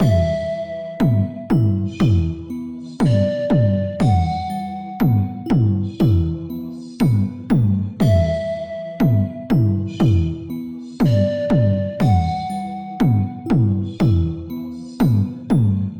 SunshiftersChant.ogg